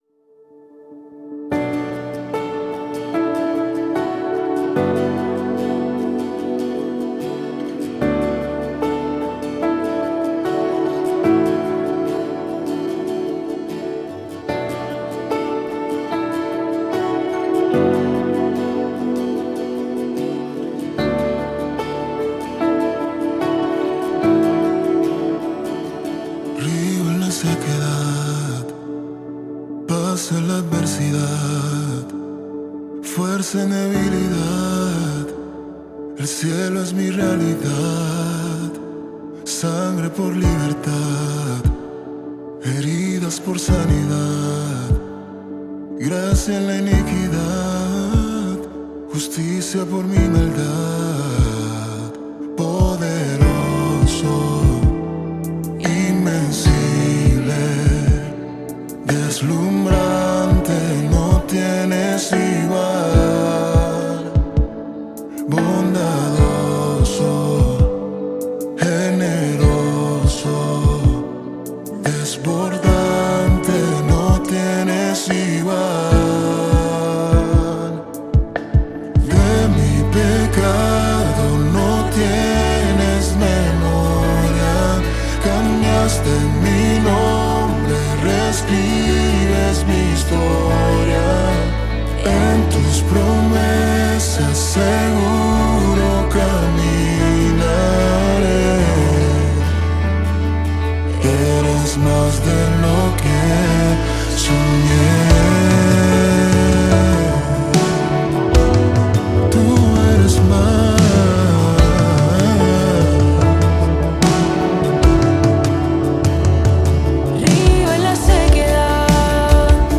Intro X2 -> pp +Todos +MelodiaPiano
Estrofa 1 -> pp +Piano +Pad
Interludio-> mf +Todos +Batería
Pre Coro 1 -> mf +GuitarElect
Tiempo: 74Bpm
Métrica: 4/4
Tonalidad: D – (1/2 tono abajo)